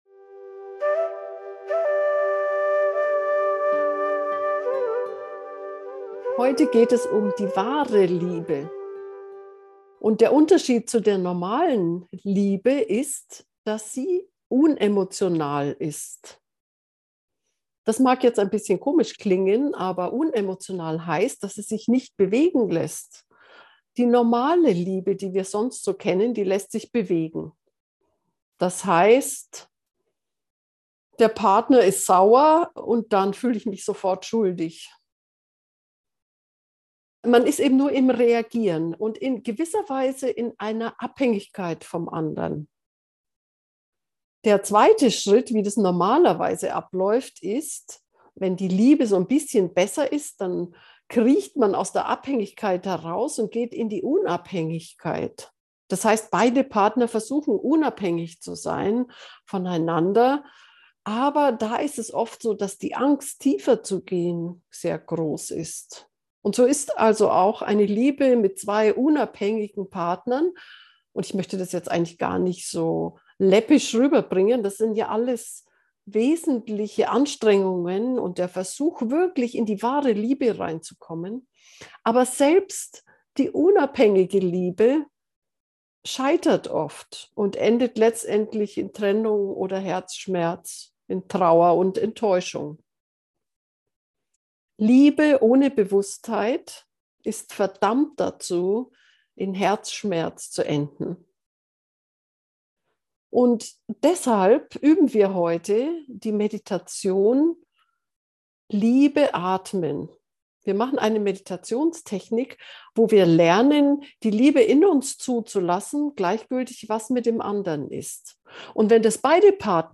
liebe-braucht-meditation-gefuehrte-meditation